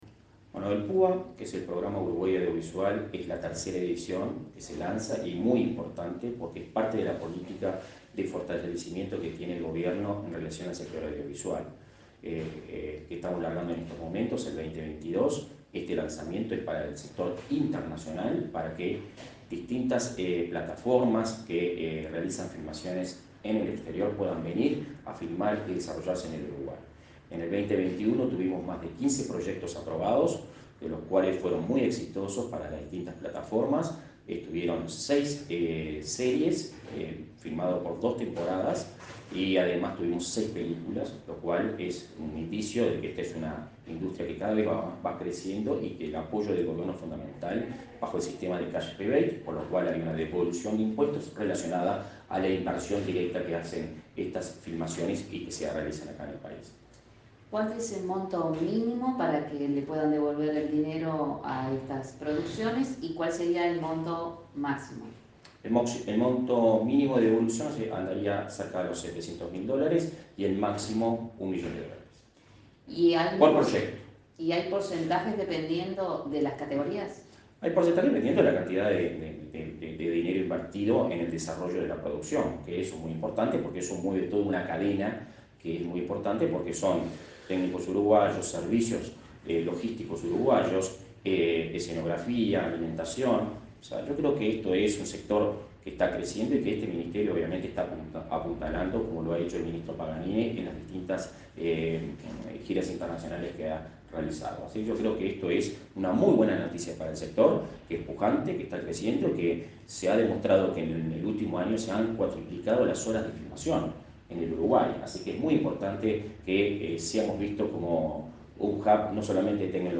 Entrevista a Guzmán Acosta y Lara